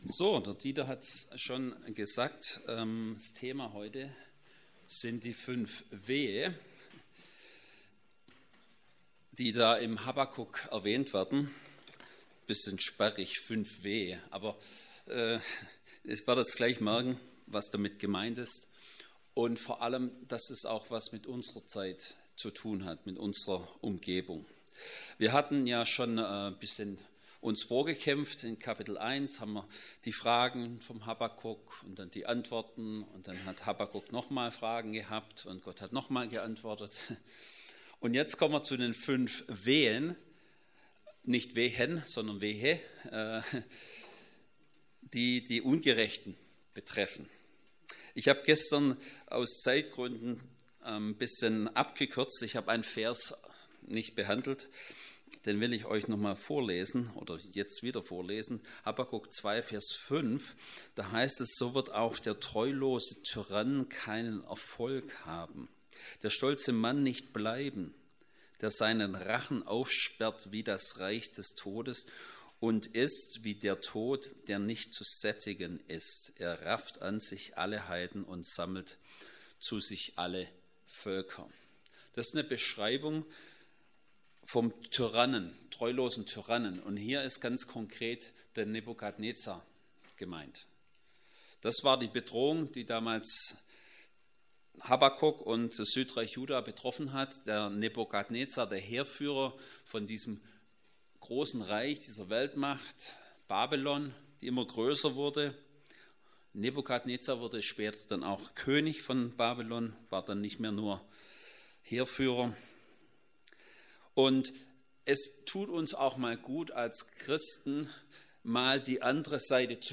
Habakuk 2,6-20 Predigt.mp3